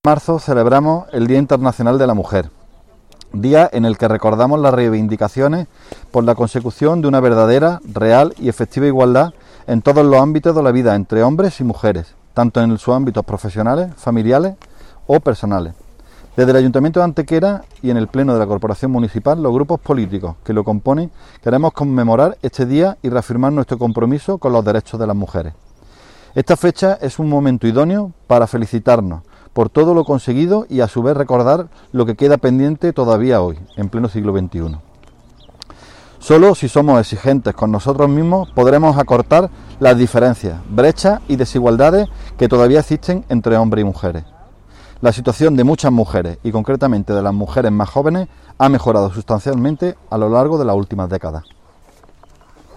El teniente de alcalde delegado de Igualdad, Alberto Arana, y la concejal de Equidad, Sara Ríos, han leido ante los medios de comunicación dicho texto al no poder realizarse concentraciones masivas debido a las restricciones sanitarias de la pandemia.
Cortes de voz